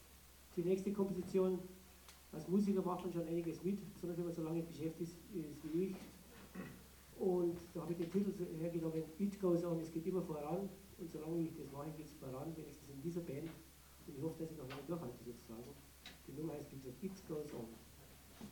Konzert (Tufa, Trier)
04 - Ansage.mp3